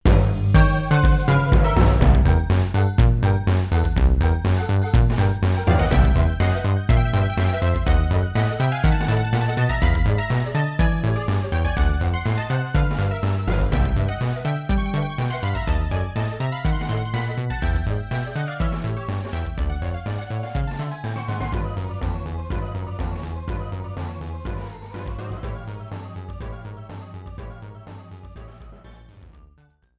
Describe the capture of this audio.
contribs)Proper fadeout.